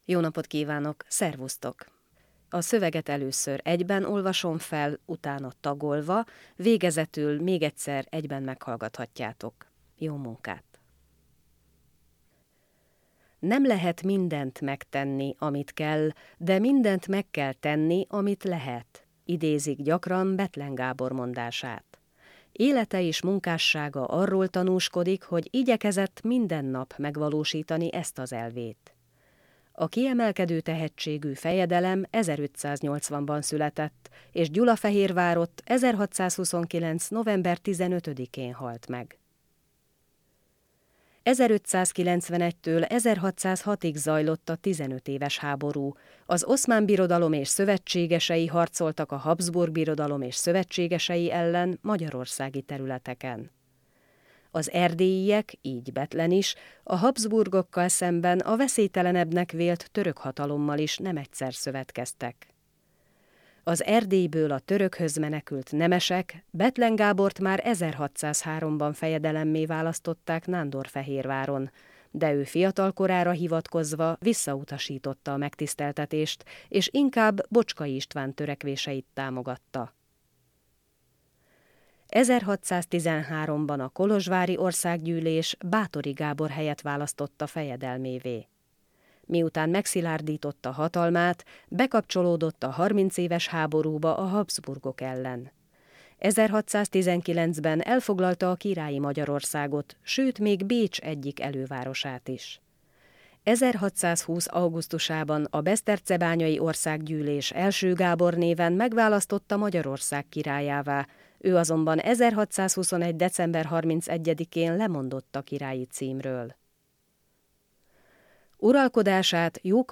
Letölthető anyagok: Tollbamondás hanganyag itt tölthető le 2021. február 26. 08:30-tól (ha hem kattintható a szöveg, frissítse az oldalt) Tollbamondás munkalap Tollbamondás jegyzőkönyv